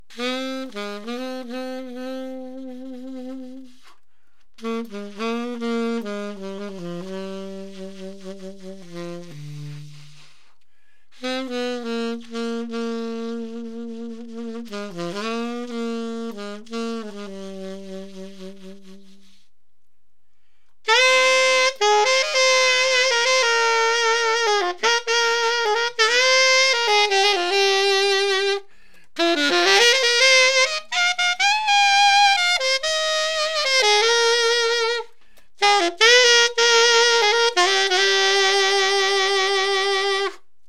Hier spiele ich in einer nicht geschnittenen Aufnahme alles mit dem gleichen von HWP klangoptimierten Setup:
du hast mit deiner wunderschönen Aufnahme nicht verschiedene Sounds im eigentlichen Sinne erzeugt, sondern einfach mal Subtones gespielt, und mal gegrowlt, also verschiedene offensichtliche "Effekt"techniken angewandt.
Natürlich ist das eine rauchig, weich das andere irgendwie trötig und auch nervig, wenngleich auch nicht besonders spitz.
weich-hart.mp3